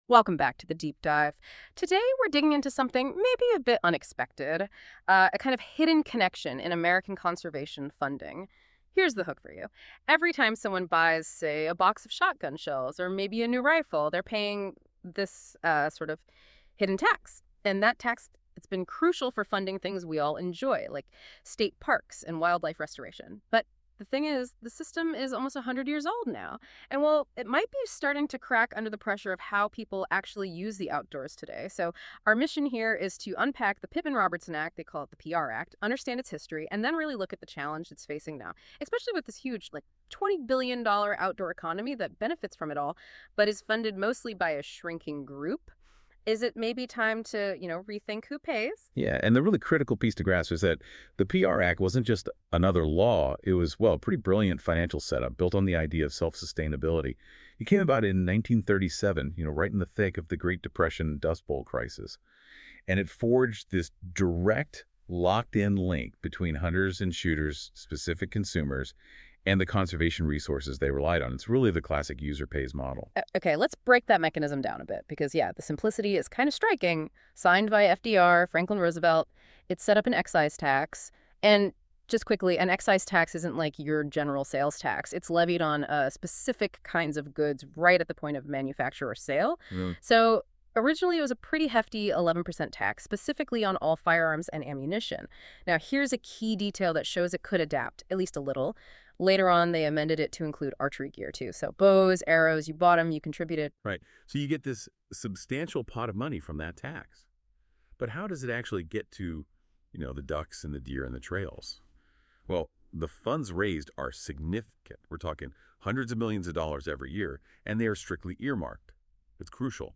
AI generated summary The source text explores the history and challenges facing the Pitman-Robertson (PR) Act, the primary financial engine for conservation in the United States.